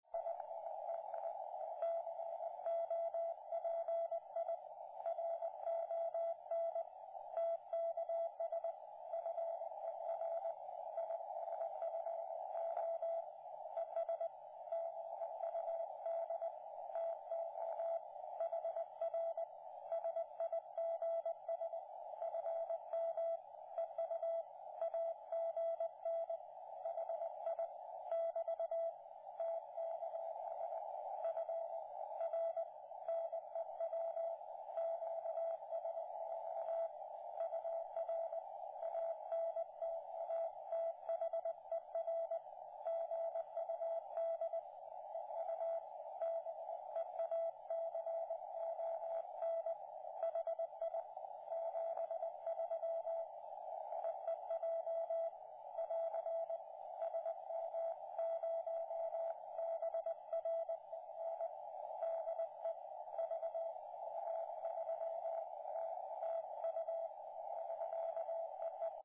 Good propagation today.